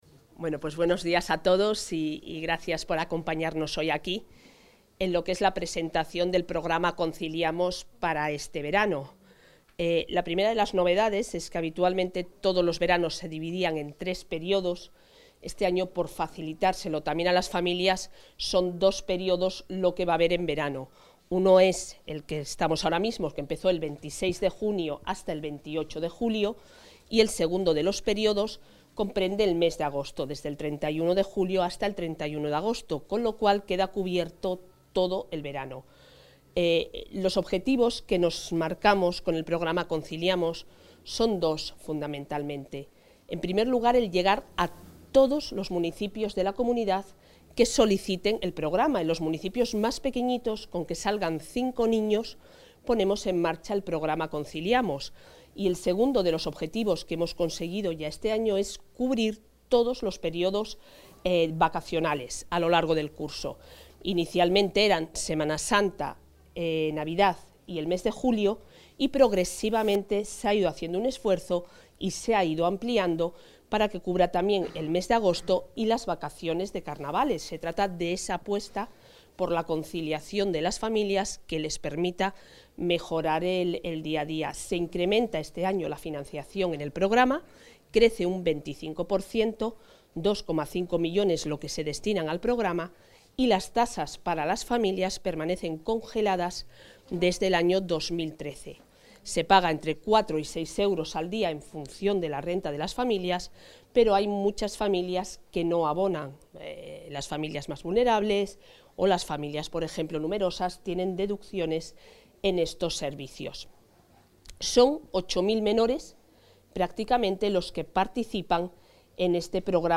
Declaraciones de la consejera de Familia e Igualdad de Oportunidades.